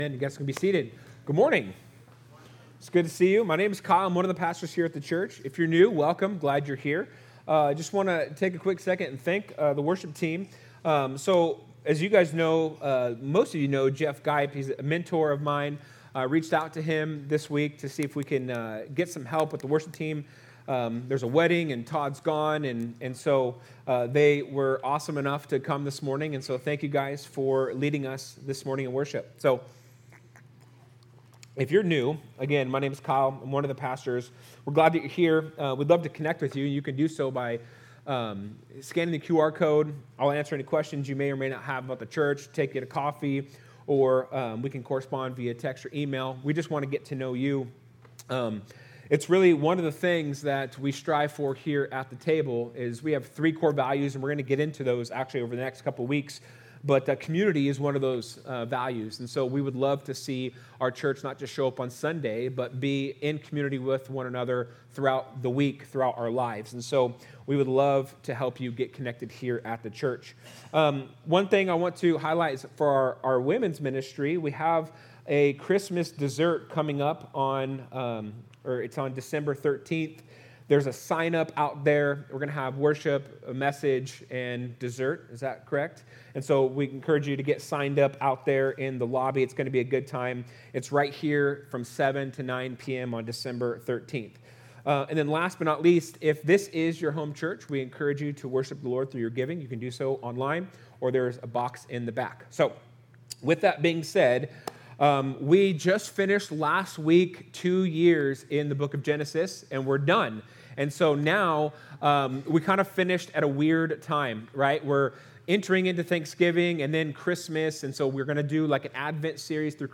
Sermons | The Table Fellowship